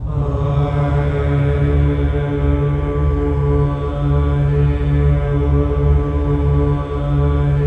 VOWEL MV03-L.wav